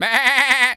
goat_baa_stressed_hurt_01.wav